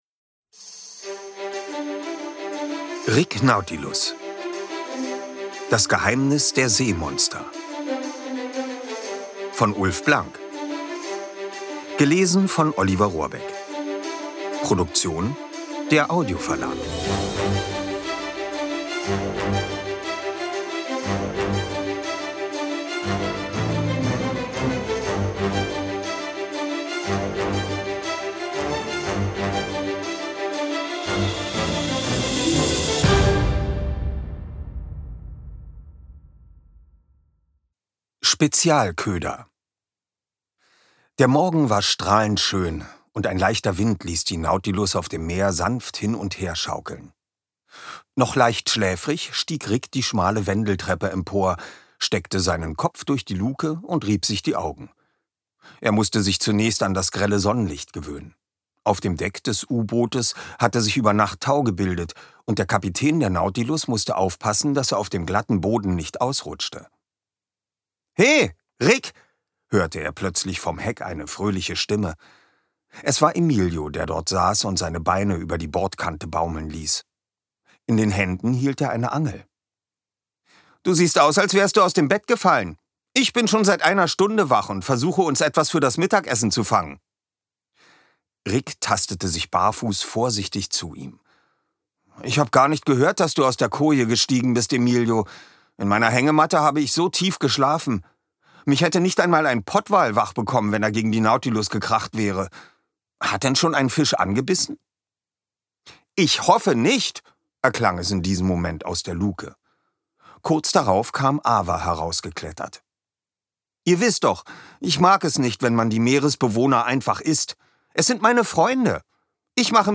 Ungekürzte Lesung mit Musik mit Oliver Rohrbeck (2 CDs)
Oliver Rohrbeck (Sprecher)